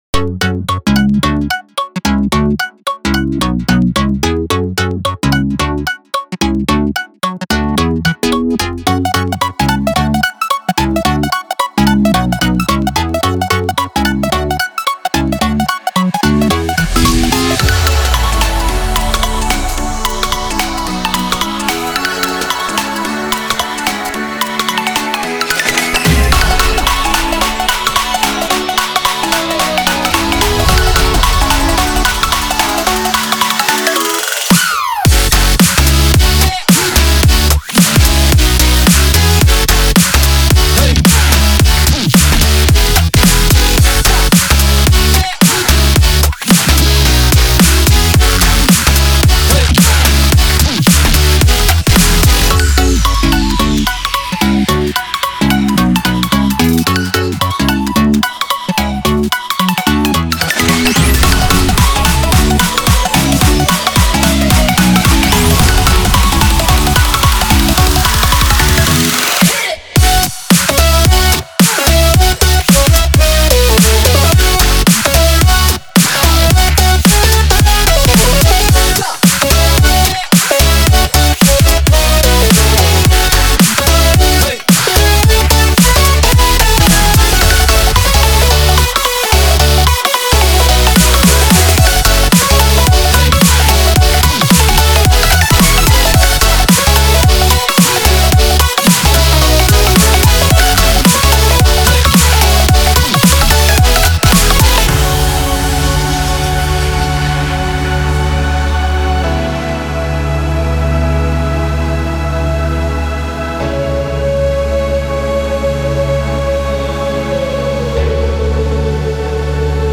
Gettin' funky with it.
Dubstep